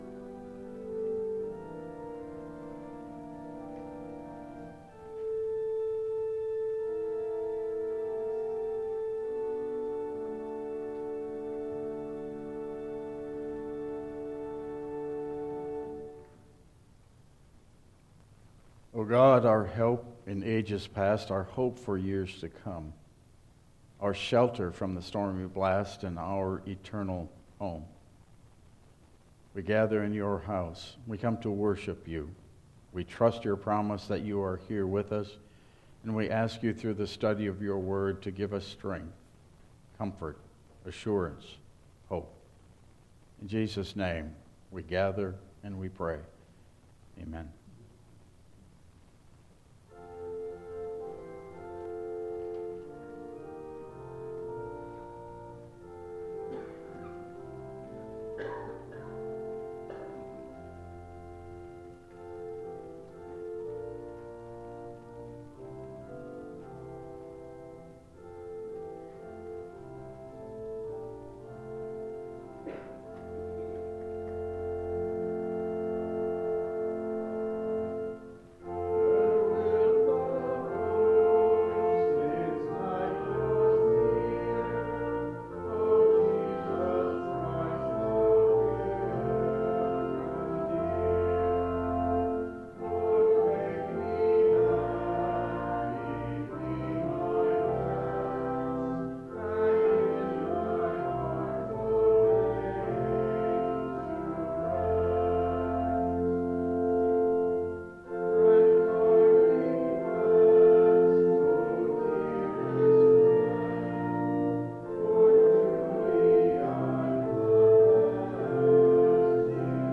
Download Files Printed Sermon and Bulletin
Passage: 2 Corinthians 11:12-15 Service Type: Regular Service